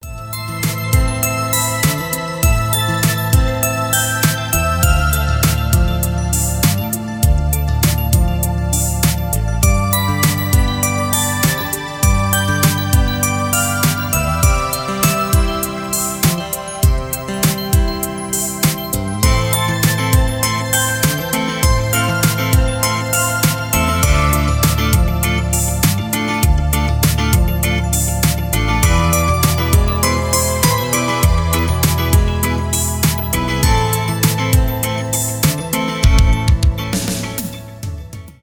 романтические , без слов
инструментальные , поп , красивая мелодия